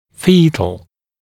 [‘fiːt(ə)l][‘фи:т(э)л]эмбриональный, зародышевый, имеющий отношение к плоду